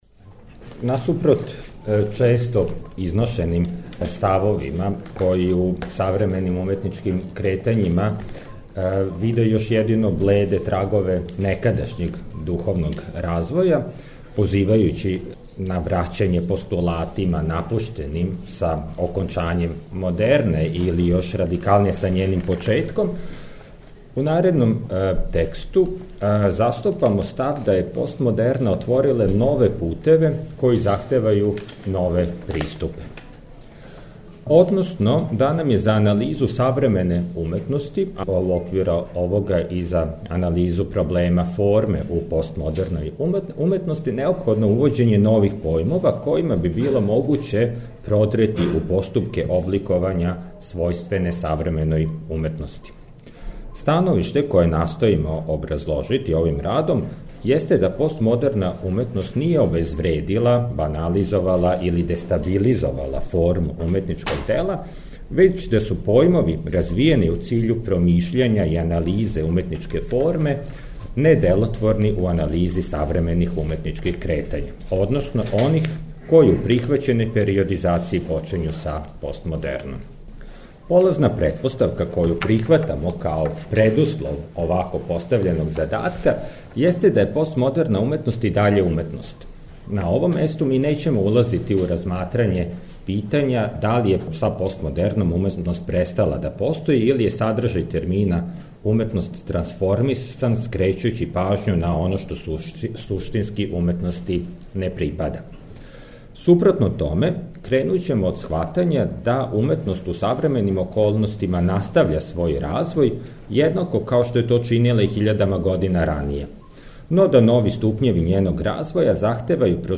У циклусу ПРОБЛЕМ ФОРМЕ четвртком и петком ћемо емитовати снимке са истоименог научног скупа који је крајем прошле године организовало Естетичко друштво Србије из Београда.
Научни скупови